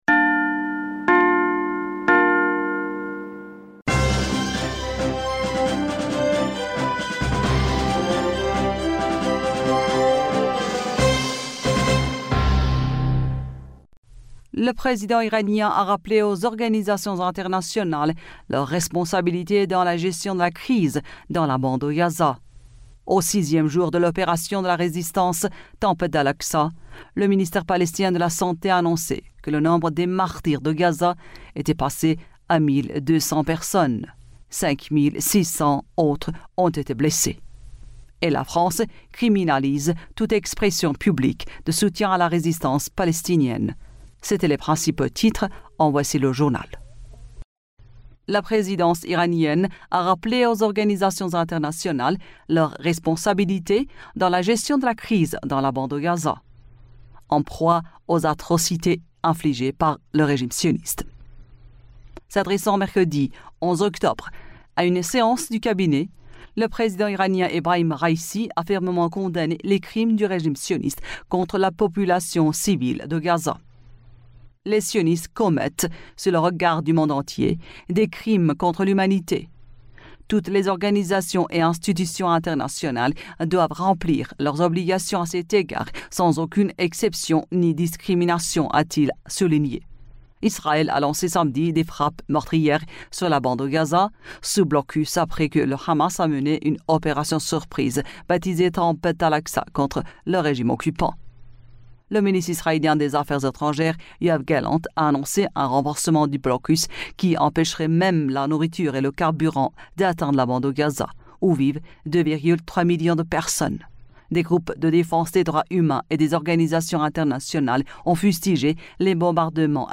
Bulletin d'information du 12 Octobre 2023